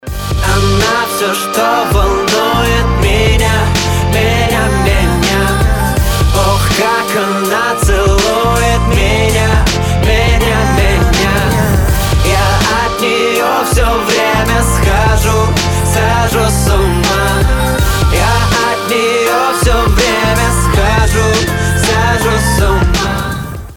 • Качество: 320, Stereo
мужской вокал
красивые
RnB